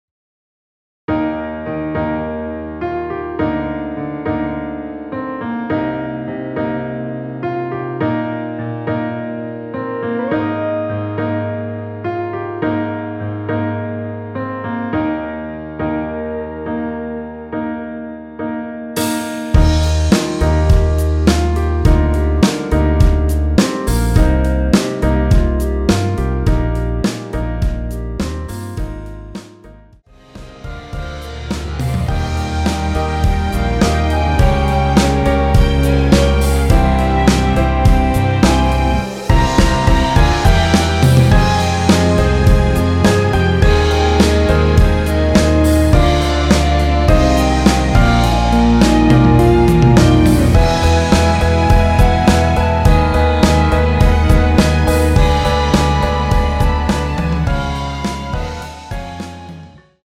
원키에서(-5)내린 멜로디 포함된 MR입니다.(미리듣기 확인)
Eb
앞부분30초, 뒷부분30초씩 편집해서 올려 드리고 있습니다.
중간에 음이 끈어지고 다시 나오는 이유는